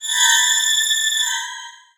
Cri de Sidérella dans Pokémon X et Y.